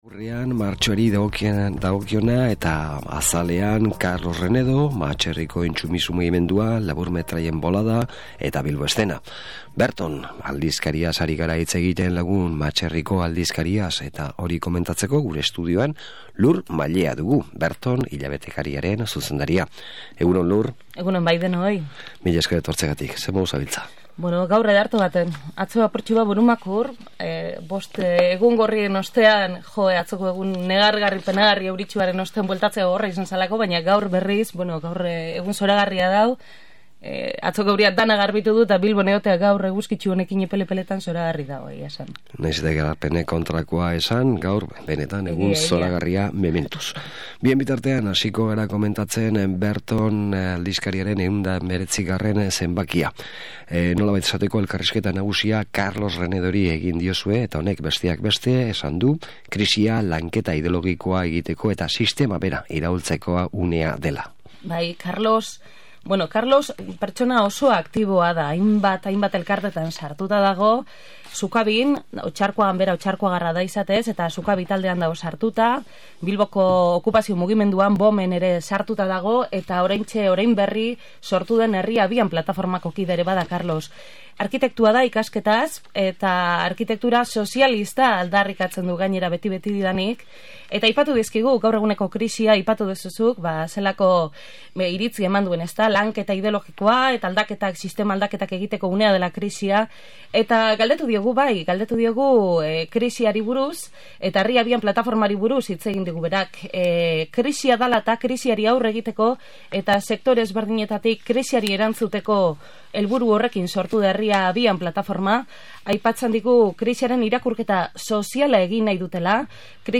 solasaldia
Elkarrizketa hau dskargatzeko klikatu HEMEN.